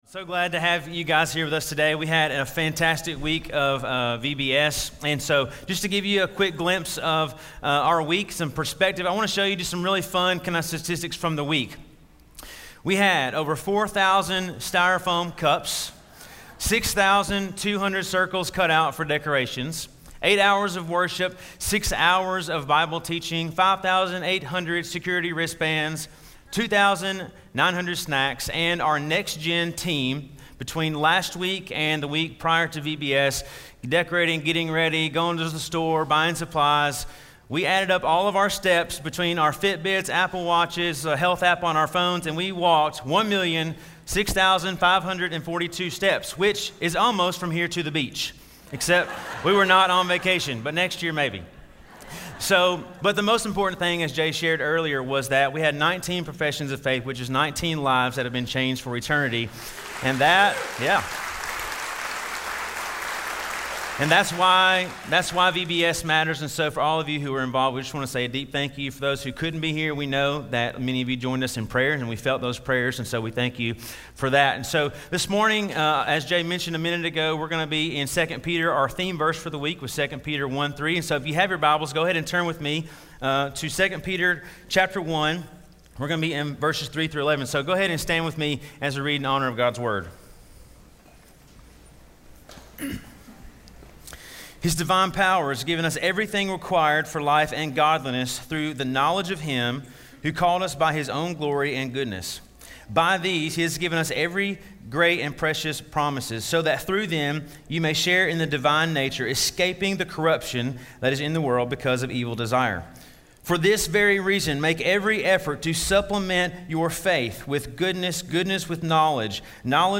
Make Every Effort - Sermon - Station Hill